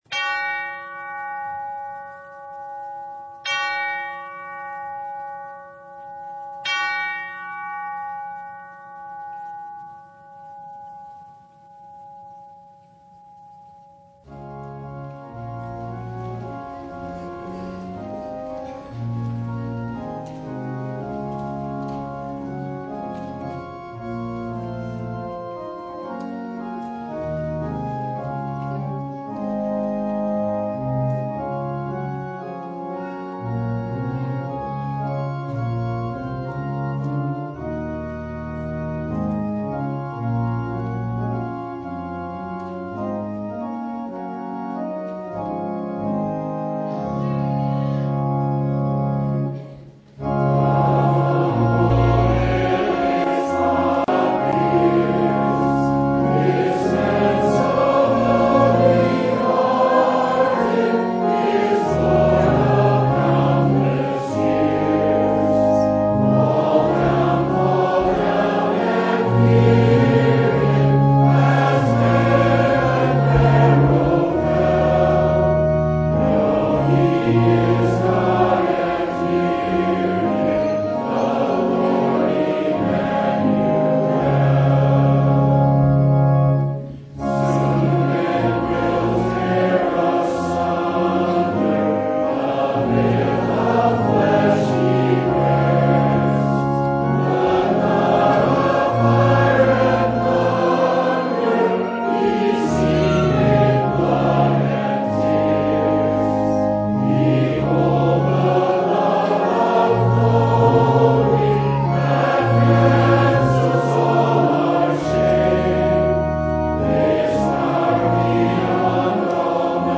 Service Type: The Transfiguration of Our Lord